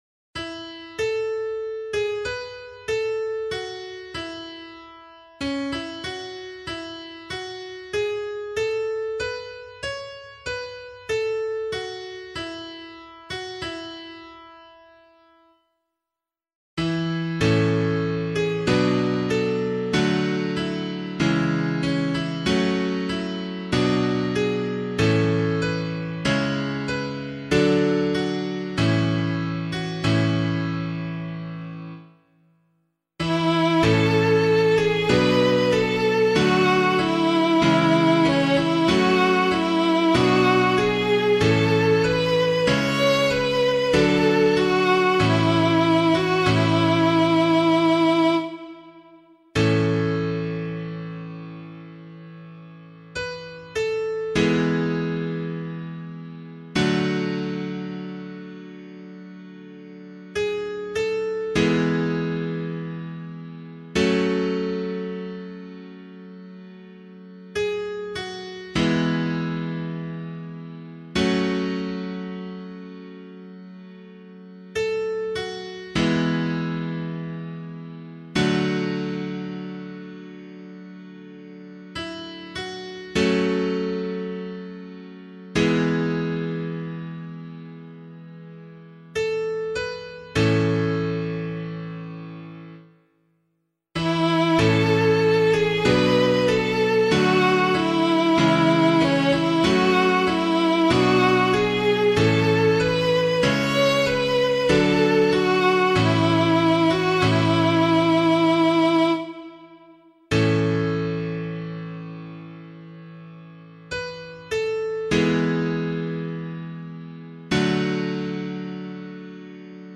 003 Advent 3 Psalm C [LiturgyShare 8 - Oz] - piano.mp3